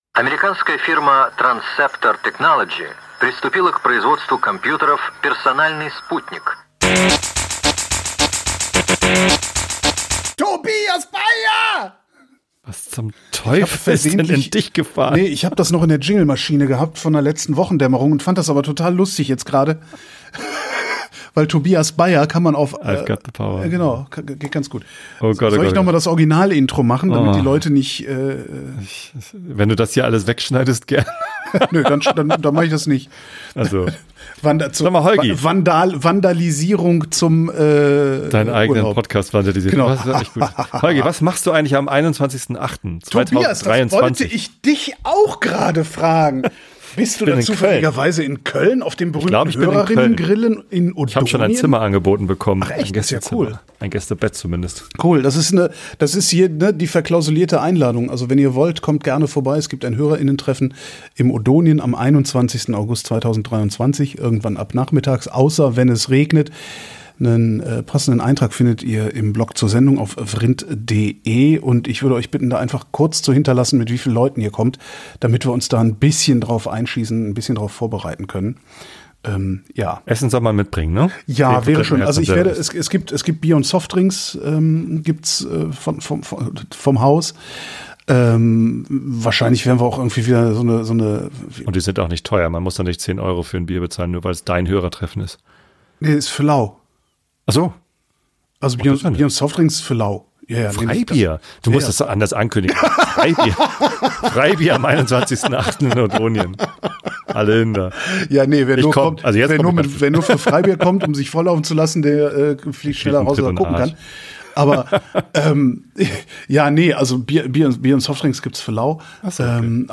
Ein Laberpodcast